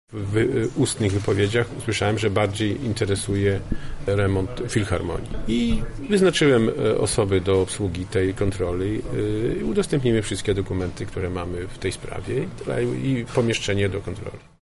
– tłumaczy marszałek Sławomir Sosnowski.